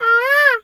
bird_peacock_squawk_09.wav